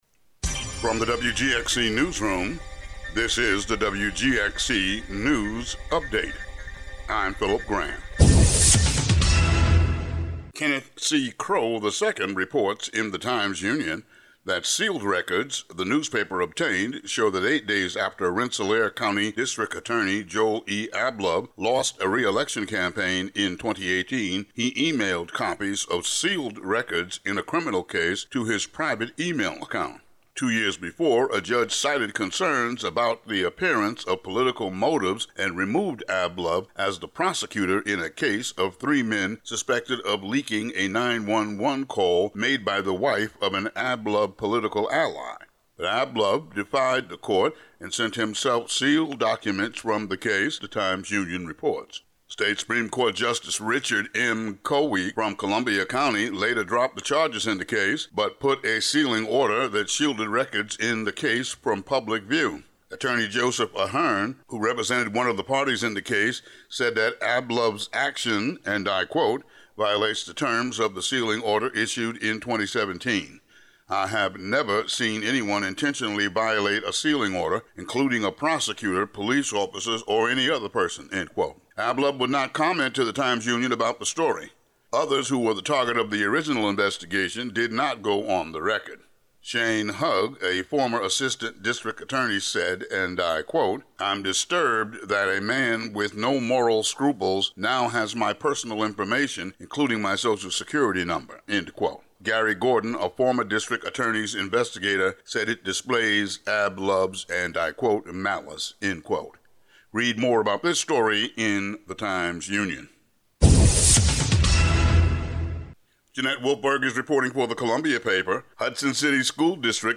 Today's local news update.